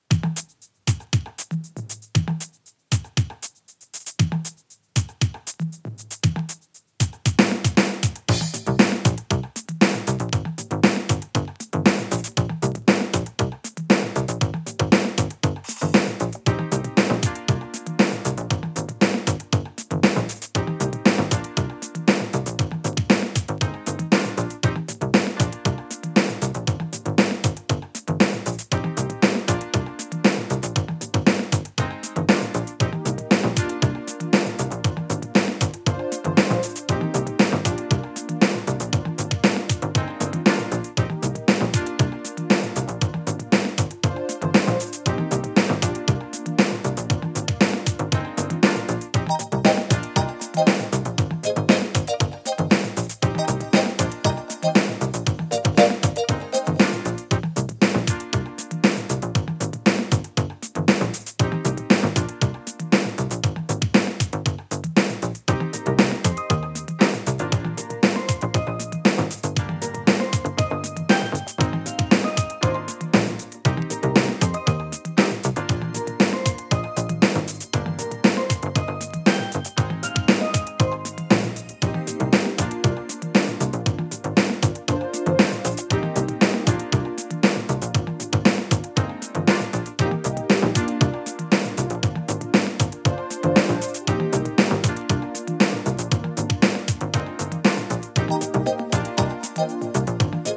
カバーラップ